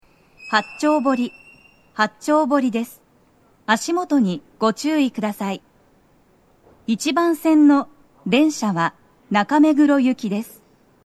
スピーカー種類 BOSE天井型
1番線には足元注意喚起放送が付帯されており、多少の粘りが必要です。
到着放送 【女声